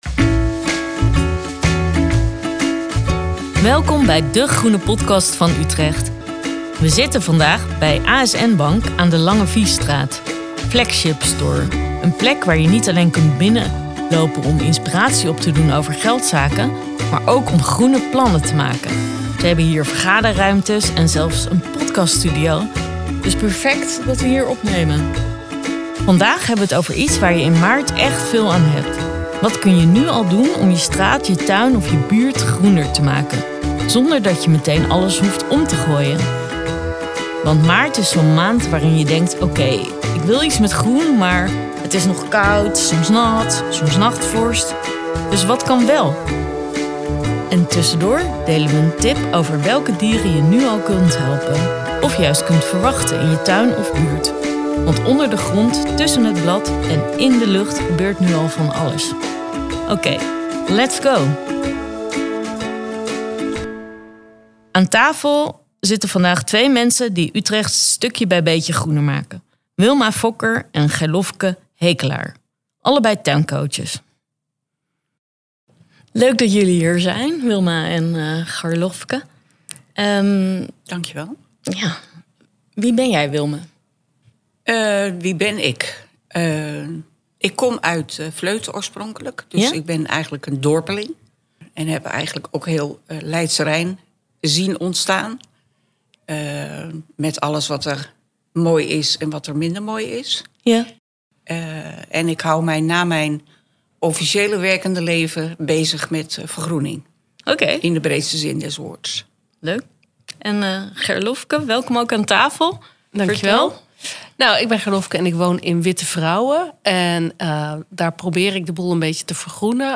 In dé groene podcast van Utrecht gaan we in gesprek met Utrechtse buurtvergroeners en hun grote en kleine buurtinitiatieven. Je hoort hoe zij begonnen zijn, het groen onderhouden en welke groene tips ze voor je hebben.